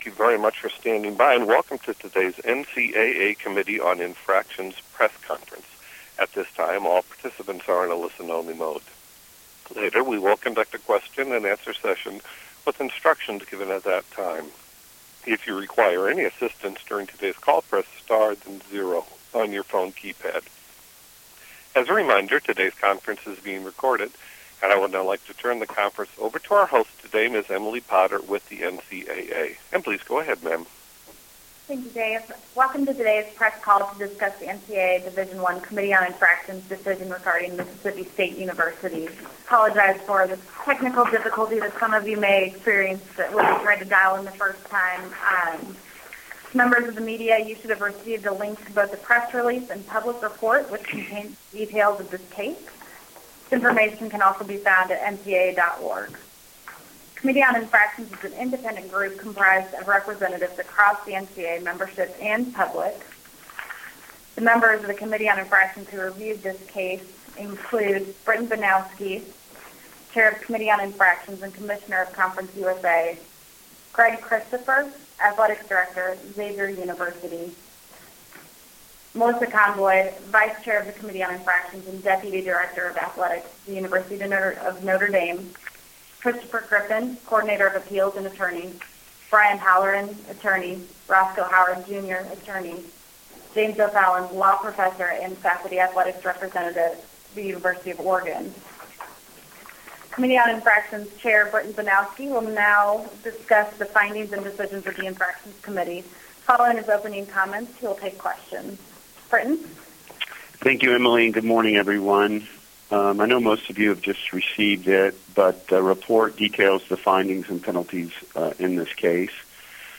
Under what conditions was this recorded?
Telephonic press conference to discuss the NCAA Division I Committee on Infractions' decision regarding Mississippi State